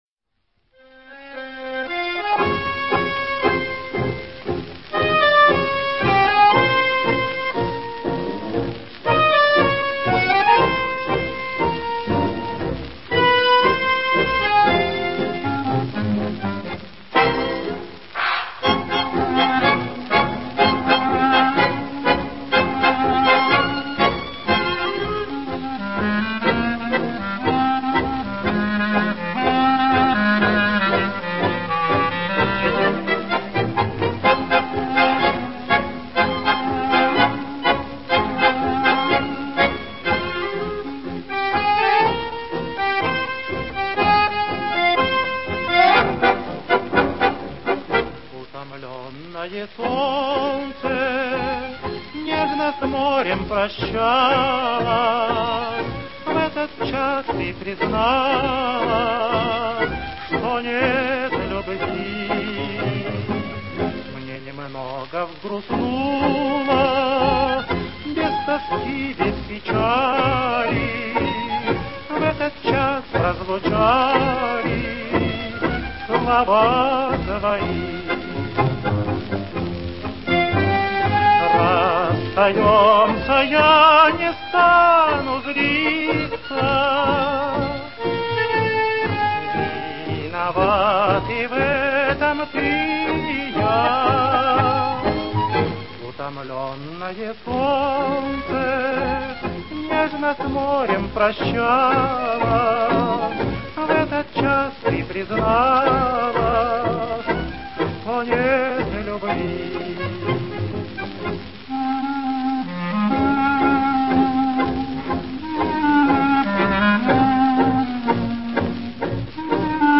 джаз-оркестра
Москва, 1937 год.